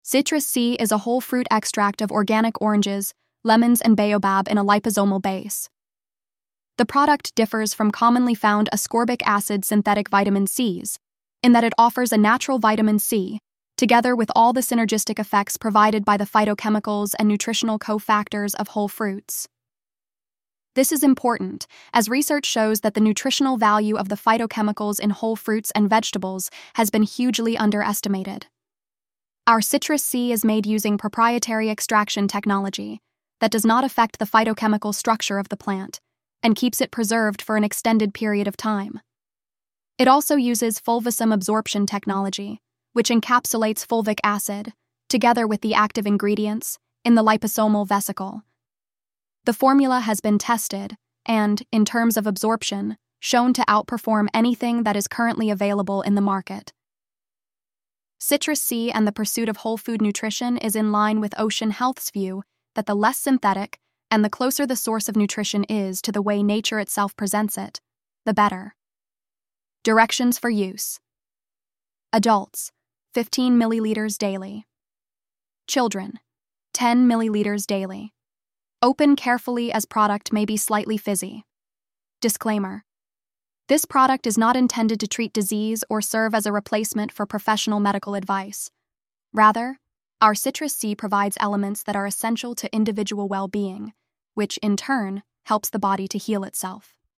Narrated Product Information.
Oshun-Health-Citrus-C-Voiceover.mp3